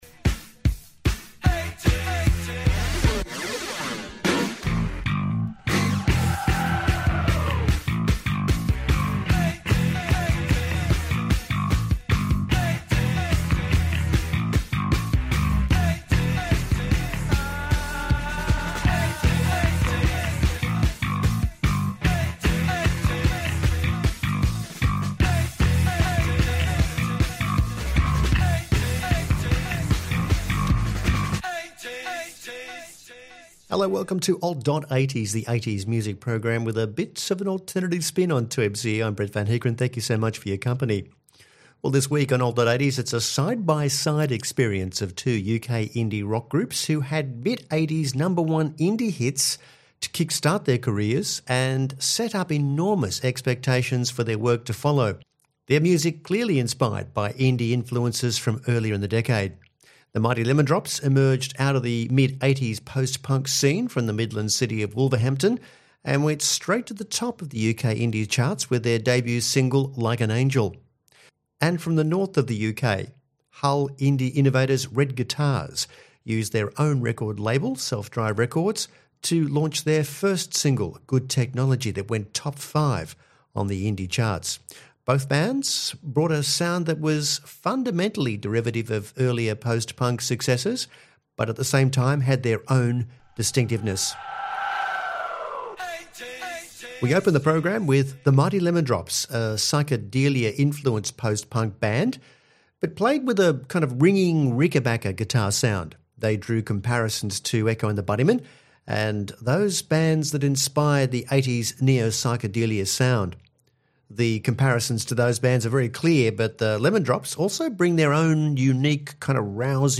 UK Indie rock groups
post-punk